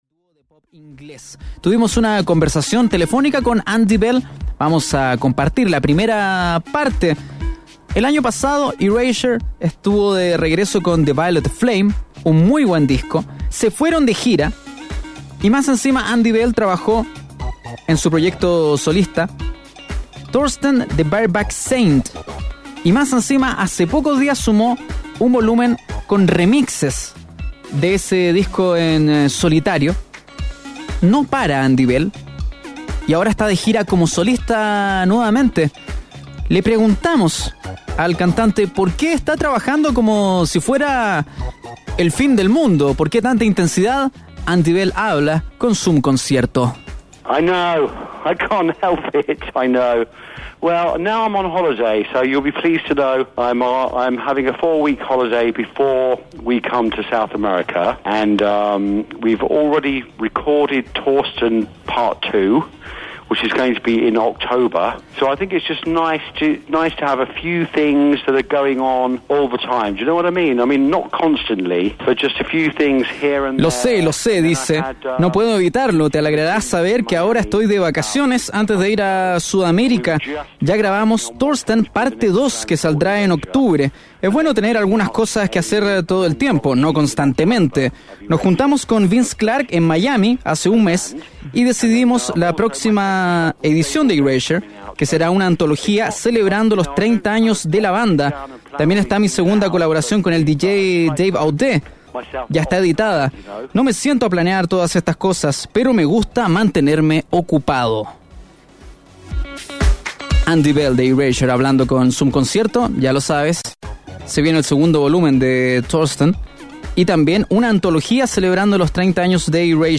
El cantante de Erasure conversó con la 88.5. Escucha la entrevista completa.